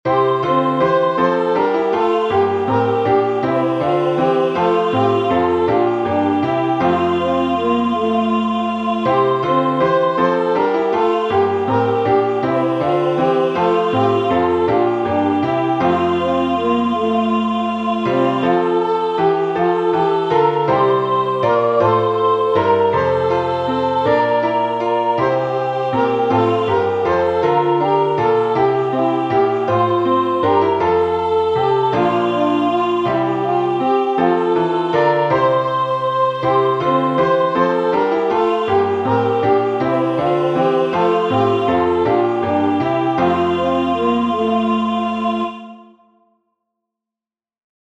Acclamation (UK 24)Behold the virgin shall conceive